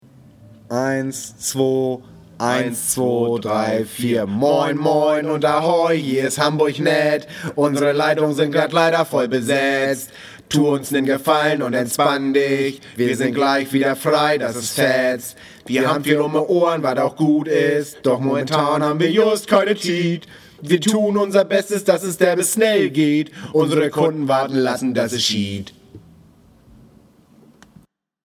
Warteschleifenmusik.